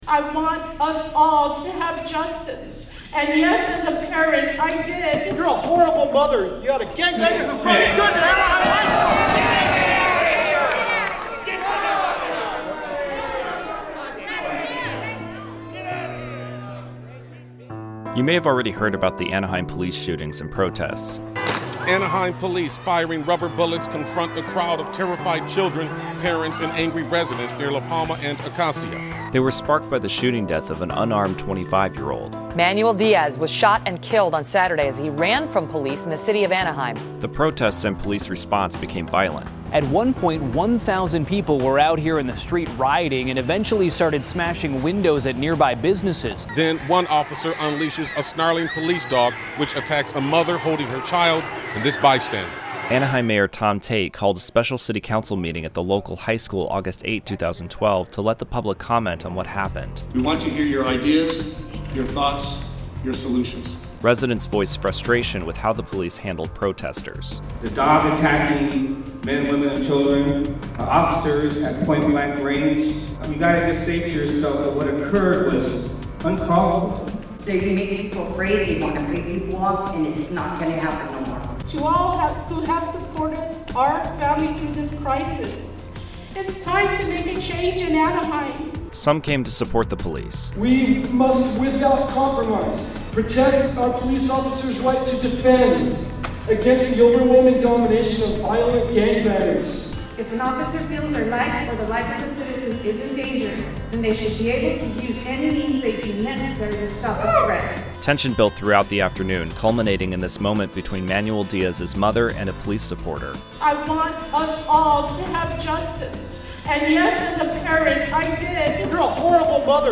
Residents of Anaheim, Calif. got a chance to react to a recent rash of police shootings when the mayor, Tom Tait, held a special city council meeting on August 8, 2012.
Tension was high in the auditorium with some residents demanding change in their police department and others offering support to the officers.